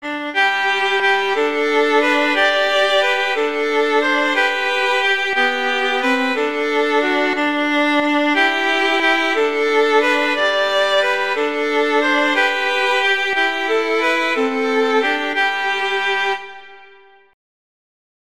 arrangements for two violins
two violins